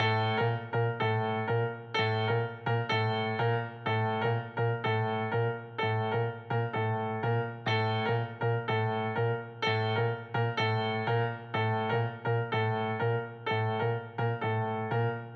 piano PPAL.wav